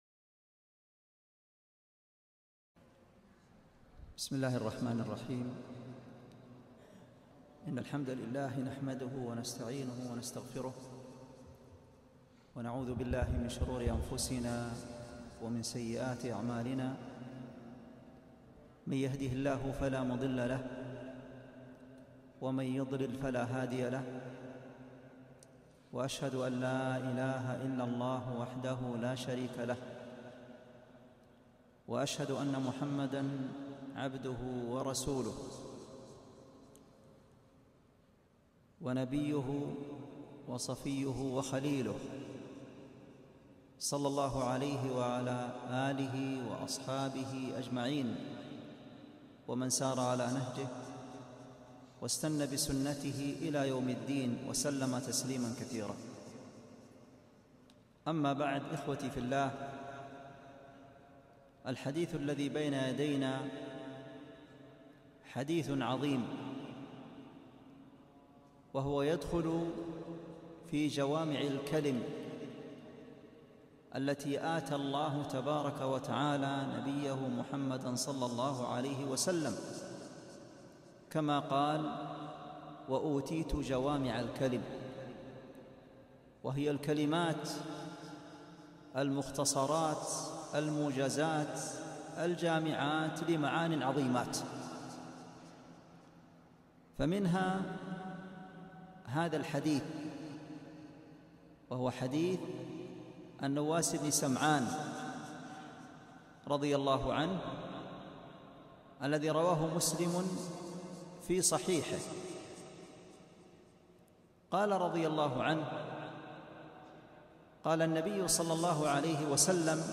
محاضرة - شرح حديث ( البر حسن الخلق )